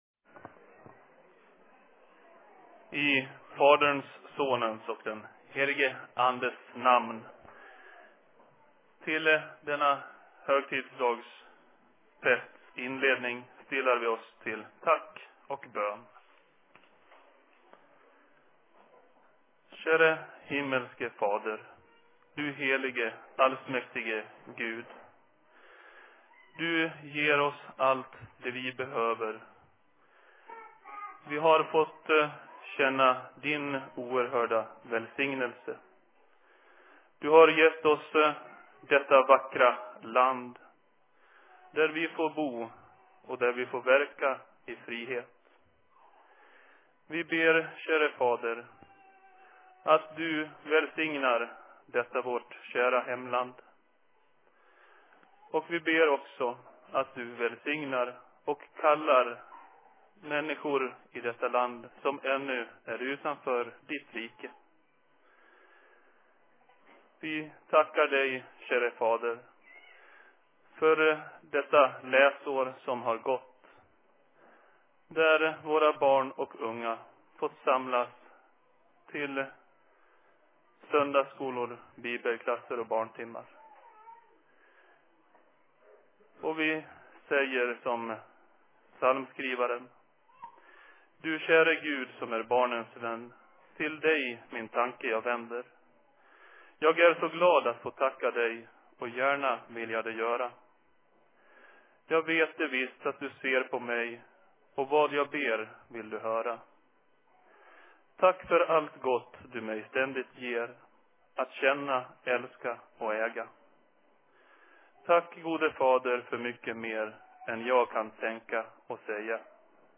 Vårfest/Kevatjuhla/Se Predikan I Dalarnas Fridsförening 06.06.2016